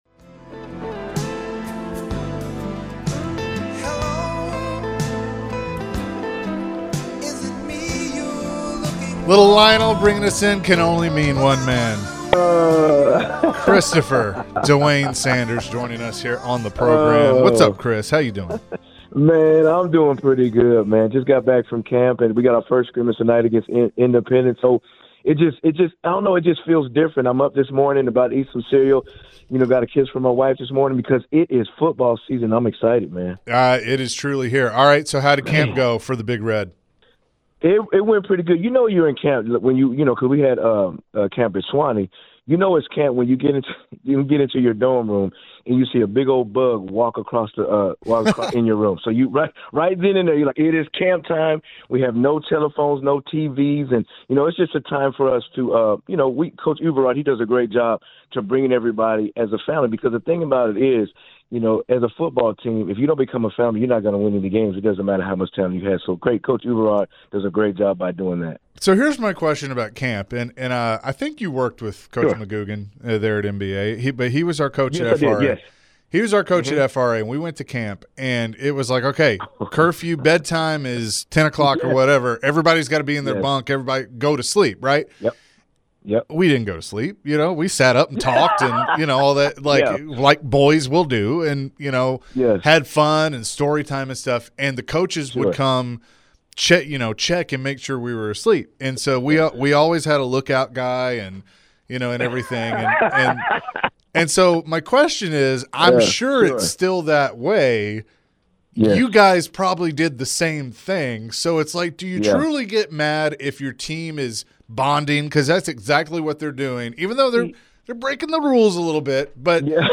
Forever Titans wide receiver Chris Sanders joined the show and shared his thoughts on training camp. Chris gave his rundown of high school football camp along with the Titans training camp.